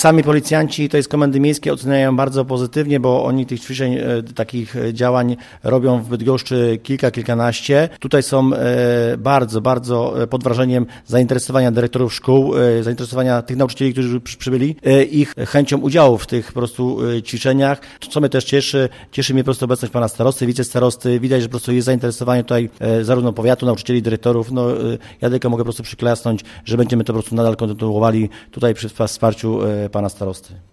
O współczesnych zagrożeniach, które mogą spotkać nauczycieli i uczniów mówił podczas spotkania Komendant Powiatowy Policji w Żninie podinsp. Artur Krajewski.